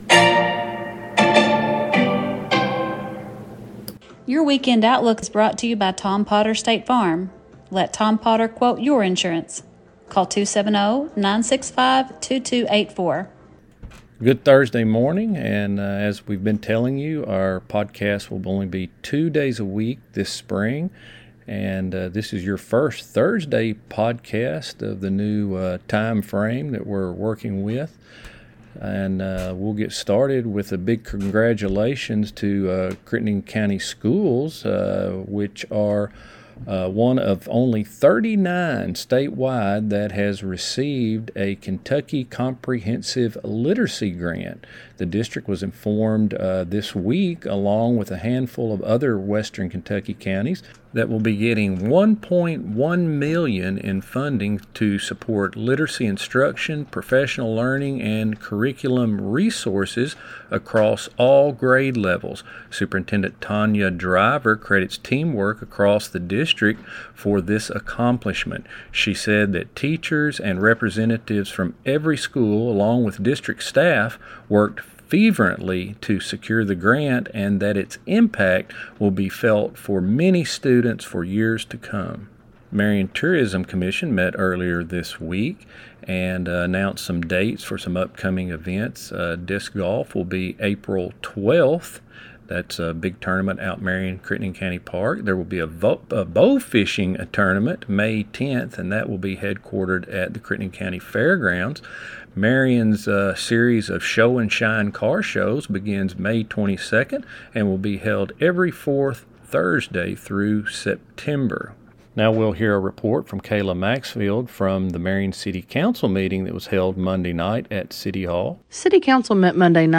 STATE FARM | Thursday NEWScast